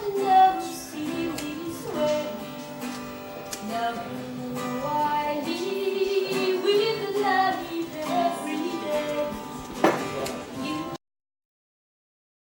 La source sonore n'est pas visible dans le champ mais fait partie de l'action.